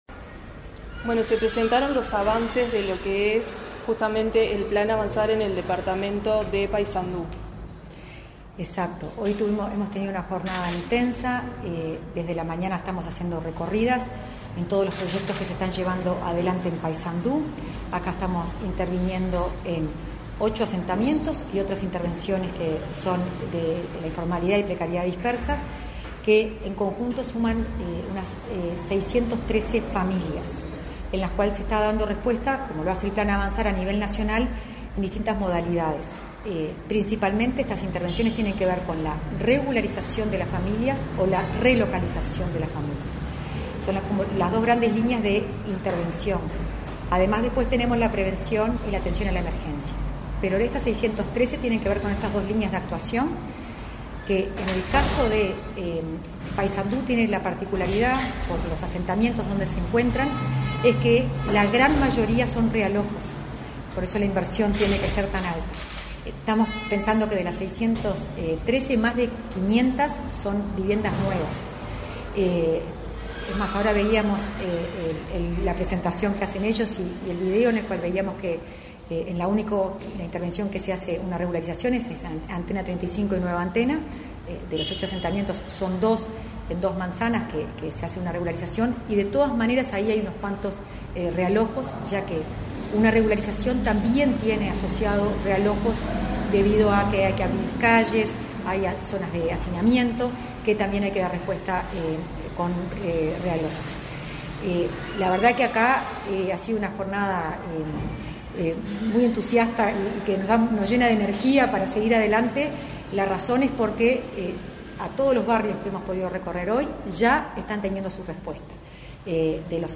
Entrevista a la directora de Integración Social y Urbana, Florencia Arbeleche